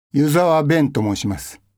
ボイスサンプル、その他